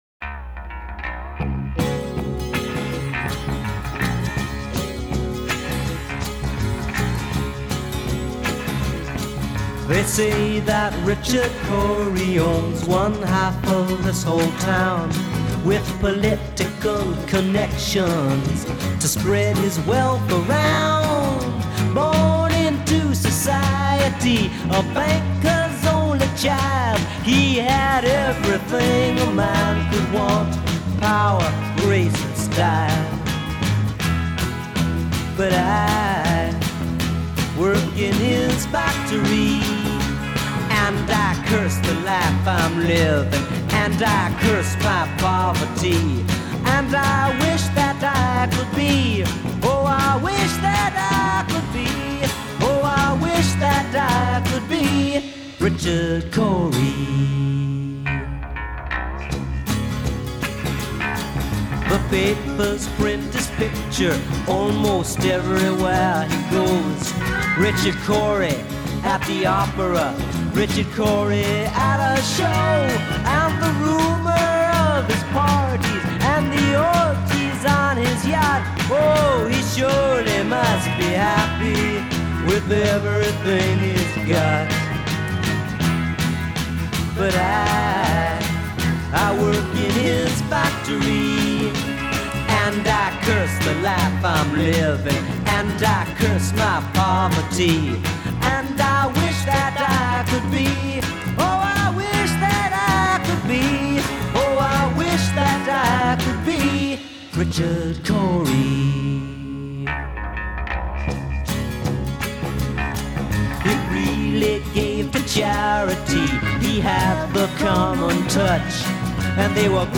Жанр: Folk, Soft Rock